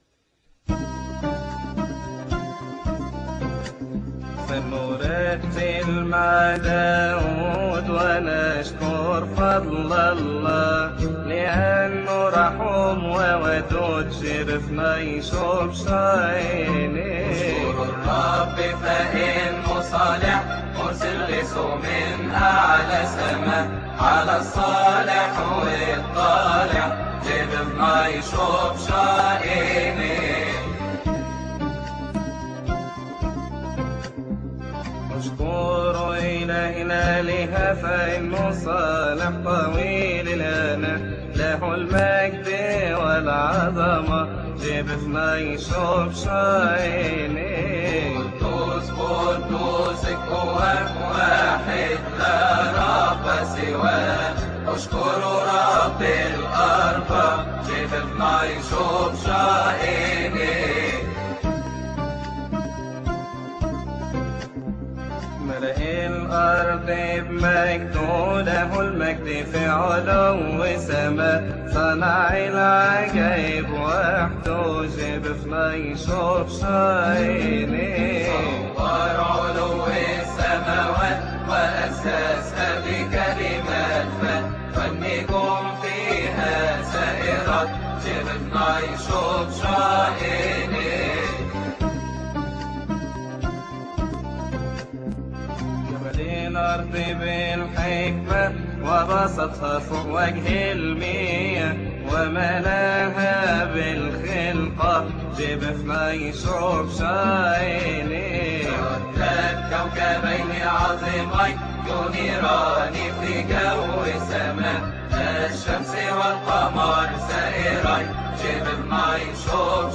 مديح فلنرتل مع داود
• المصدر : فريق أبو فام الجندي
مديح فلنرتل مع داود يقال بعد لبش الهوس الثاني في تسبحة نصف الليل بشهر كيهك لفريق ابو فام الجندي، عربي